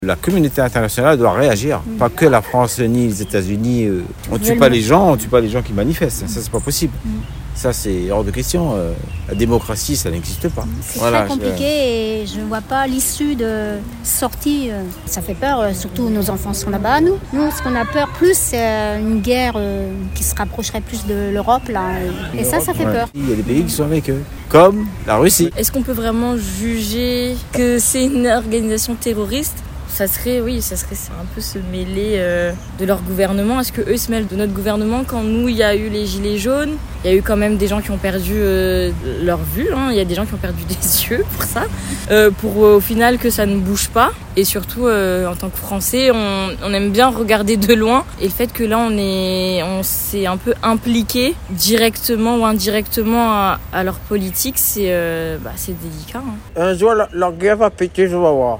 Des avis variés, entre inquiétude, incompréhension et fatalisme, face à un monde jugé de plus en plus instable.